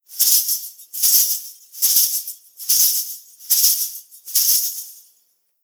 Danza árabe, bailarina da un golpe de cadera con un pañuelo de monedas, movimiento continuo 02
Sonidos: Acciones humanas